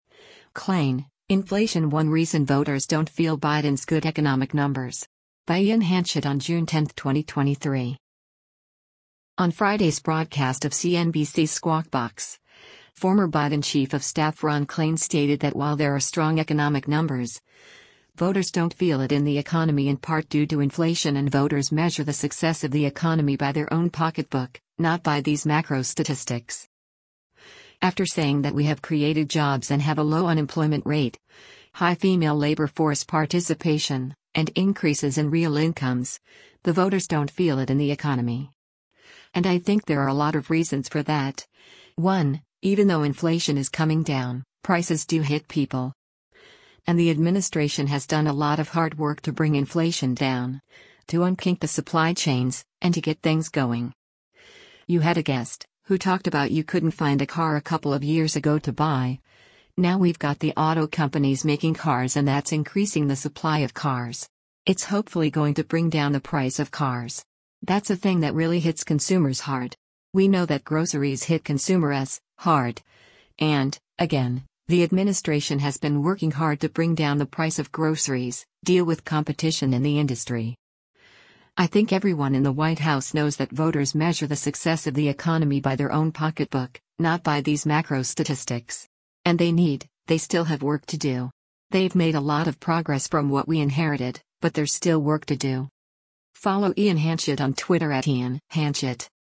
On Friday’s broadcast of CNBC’s “Squawk Box,” former Biden Chief of Staff Ron Klain stated that while there are strong economic numbers, “voters don’t feel it in the economy” in part due to inflation and “voters measure the success of the economy by their own pocketbook, not by these macro statistics.”